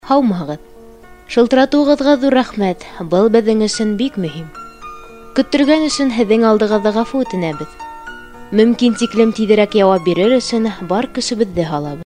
女哈萨克103T 哈萨克语女声 轻柔的 低沉|激情激昂|大气浑厚磁性|沉稳|娓娓道来|科技感|积极向上|时尚活力|神秘性感|调性走心|亲切甜美|感人煽情|素人